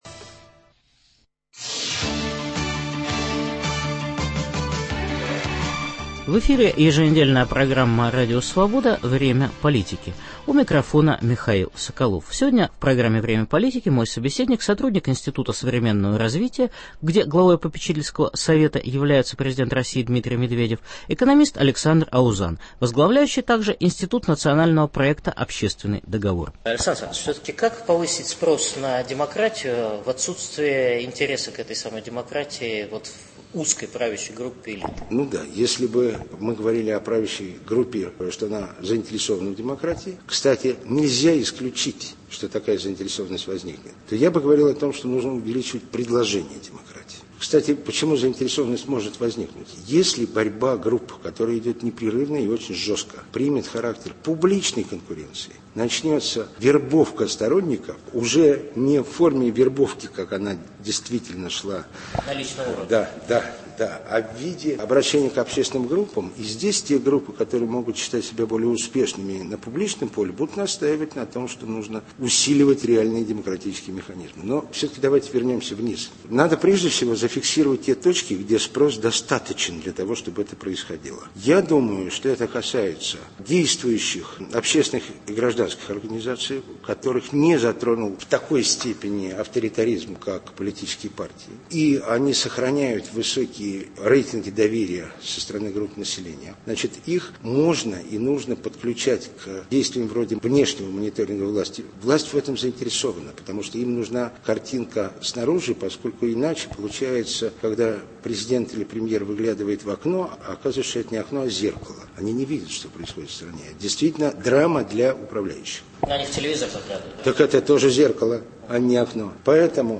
Беседа с сотрудником Института современного развития Александром Аузаном.